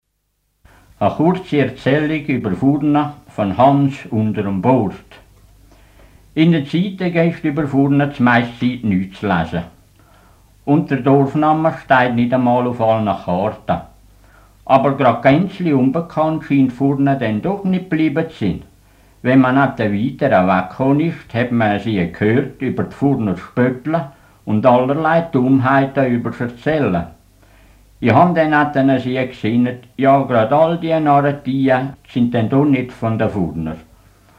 Wir hören die Stimmen klar und deutlich, und die CDs sind günstig zu kaufen.